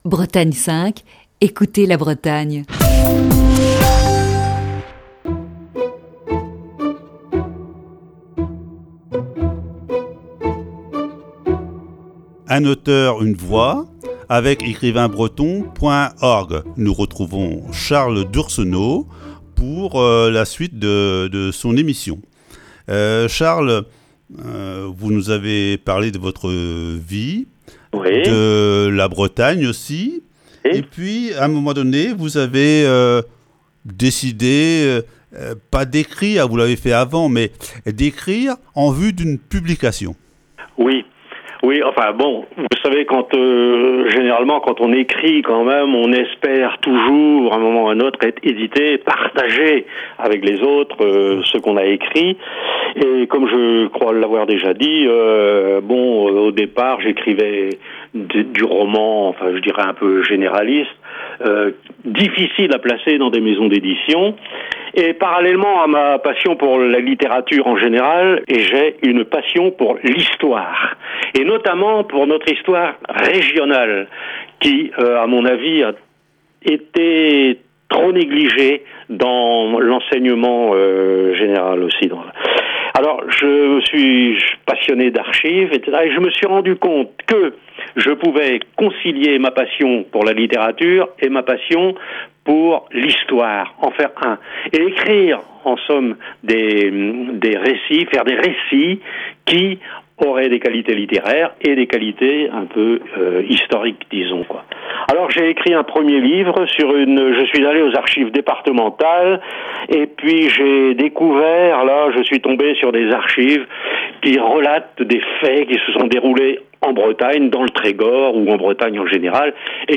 Un Auteur, Une Voix. Voici ce mercredi, la troisième partie de cet entretien.